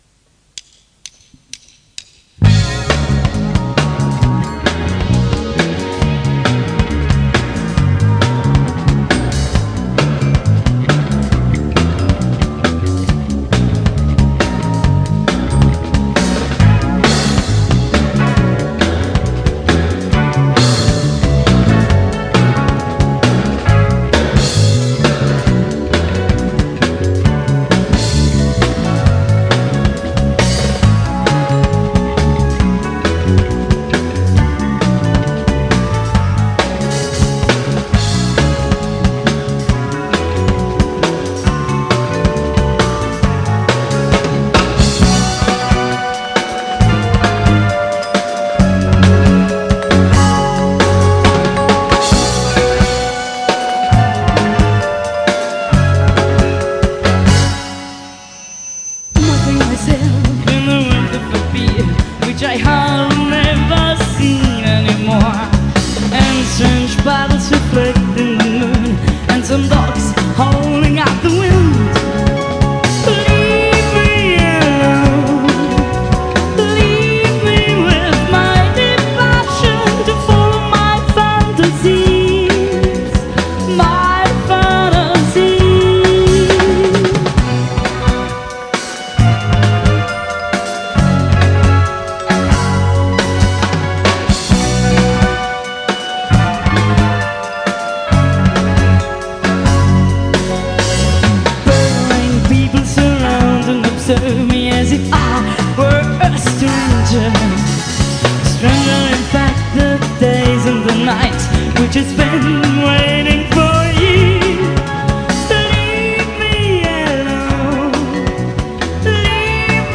• Teatro Comunale di Alessandria
Un estratto dal concerto di Alessandria